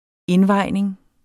Udtale [ ˈenˌvɑjneŋ ]